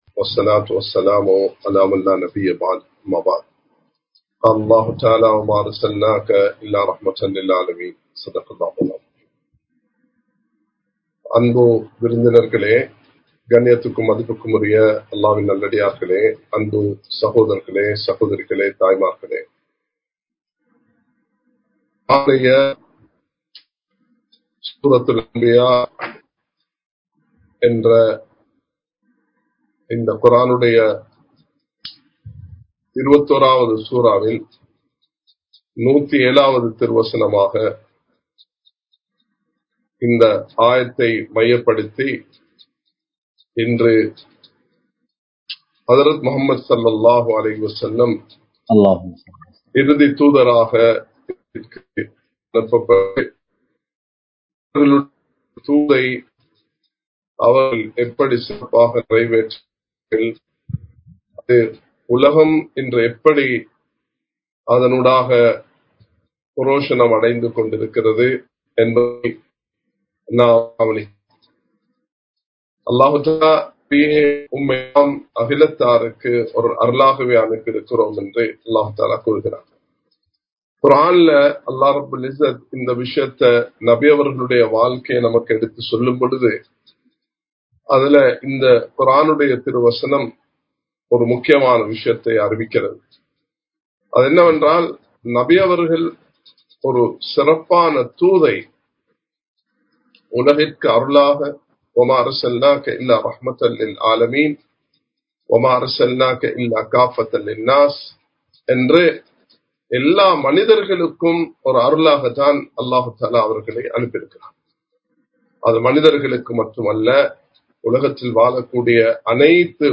நபி (ஸல்) அவர்கள் உலகத்திற்கே ஒரு அருட்கொடையாகும் | Audio Bayans | All Ceylon Muslim Youth Community | Addalaichenai
Live Stream